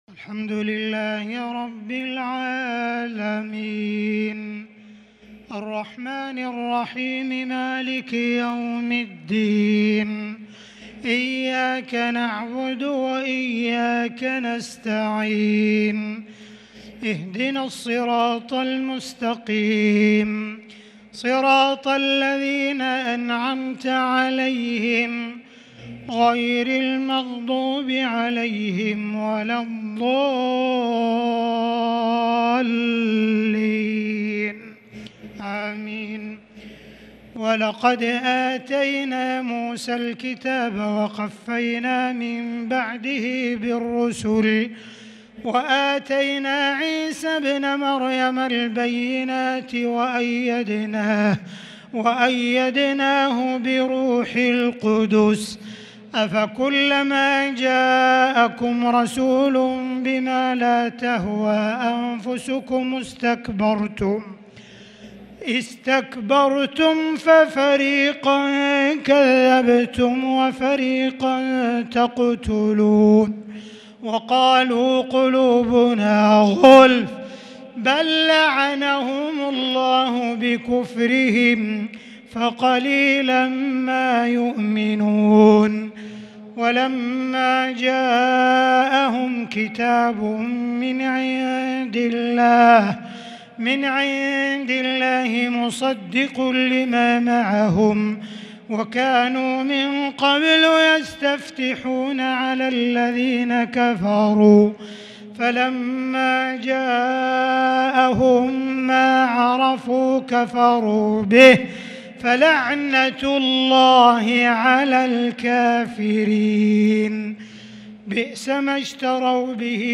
تراويح ليلة 1 رمضان 1443هـ من سورة البقرة {87-100} Taraweeh 1st night Ramadan 1443H > تراويح الحرم المكي عام 1443 🕋 > التراويح - تلاوات الحرمين